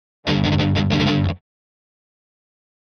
Guitar Fast Heavy Metal Finale Rhythm